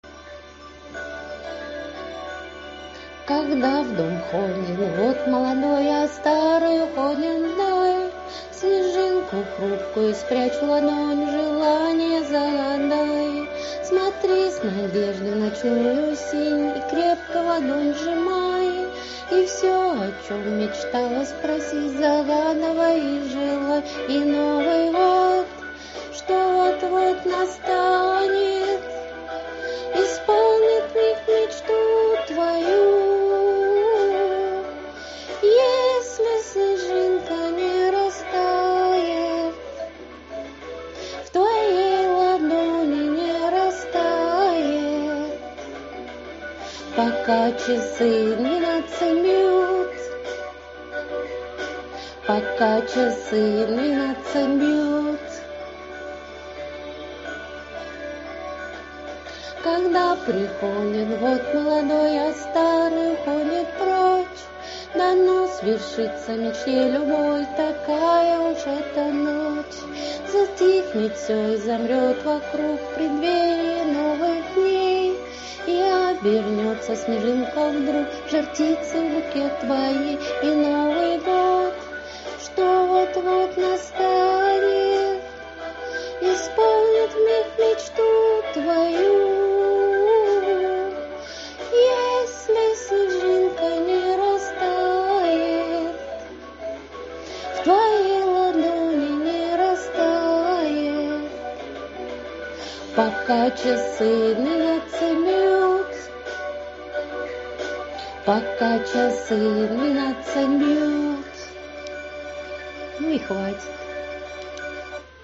но голос после болезни еще не тот
Голос хороший уверенный, мелодичный и не пропитый лекарствами!